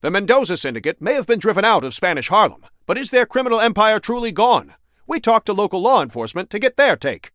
Newscaster_headline_60.mp3